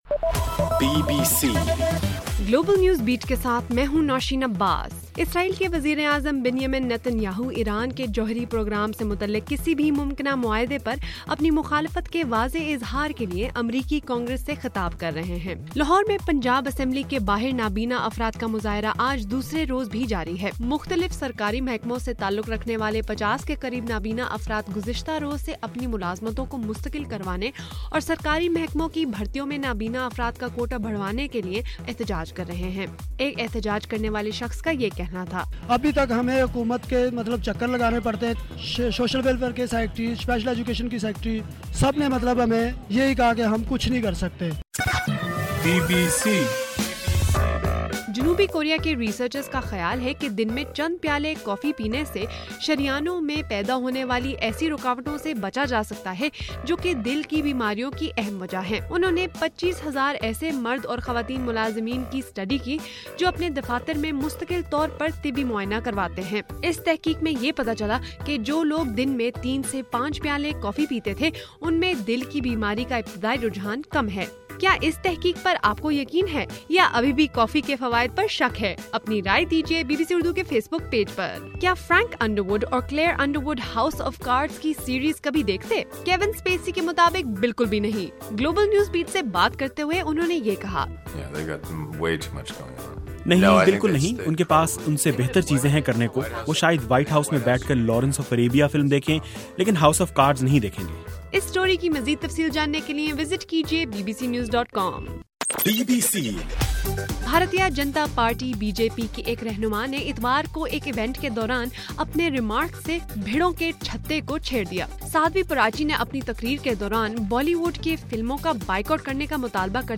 مارچ 3: رات 11 بجے کا گلوبل نیوز بیٹ بُلیٹن